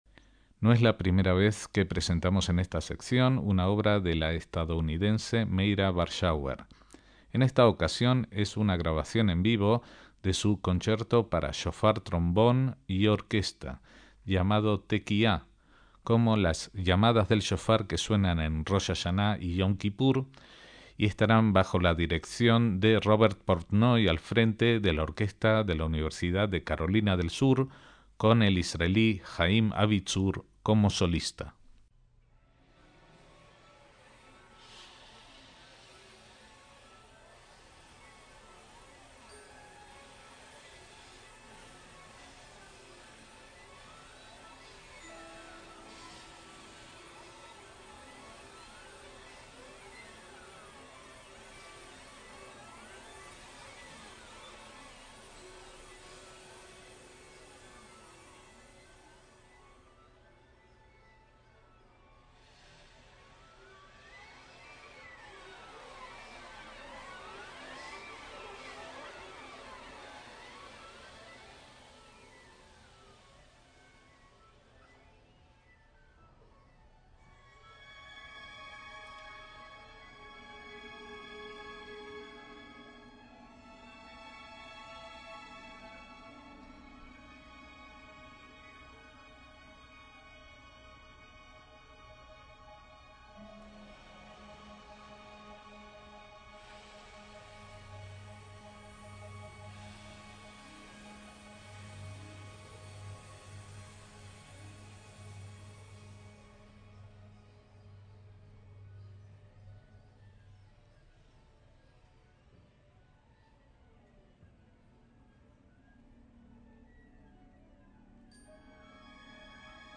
MÚSICA CLÁSICA
un concierto para shofar y orquesta